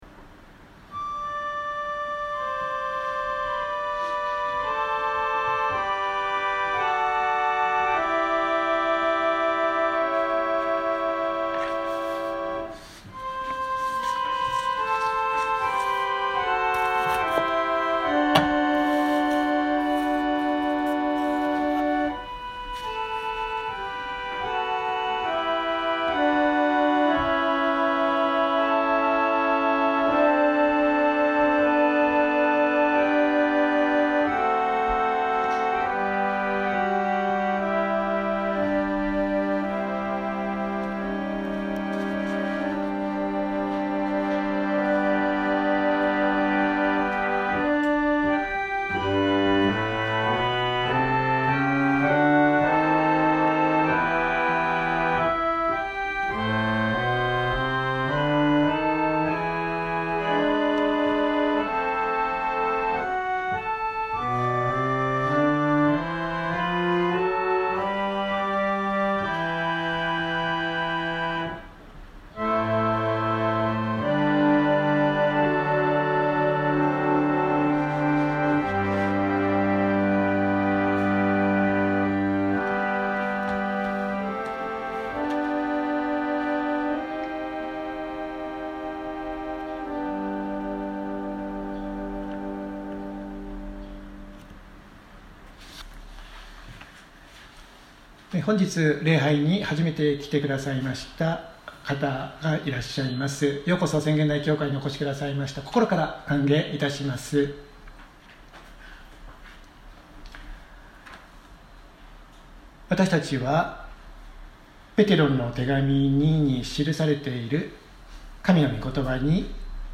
2023年06月18日朝の礼拝「主の日を待ち望む 주의 날을 기다리며」せんげん台教会
千間台教会。説教アーカイブ。